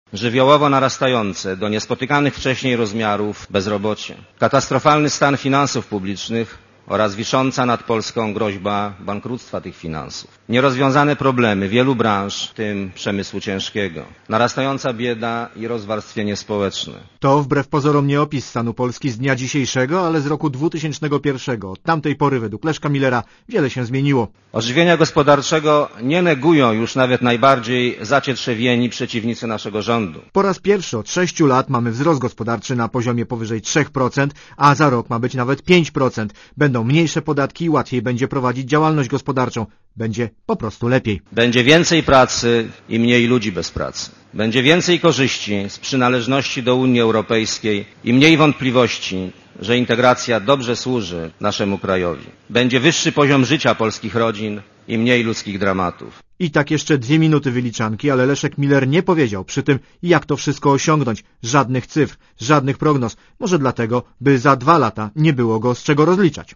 Posłuchaj premiera Millera i reportera Radia Zet (258 KB)